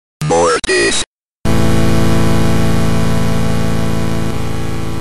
The “mortis” is a high-energy audio clip from Game SFX commonly used in memes, TikToks, and YouTube shorts to create a comedic or chaotic vibe. Featuring a punchy beat with iconic "mortis", it's a staple in modern Game SFX sound and meme culture.
Genre: sound effects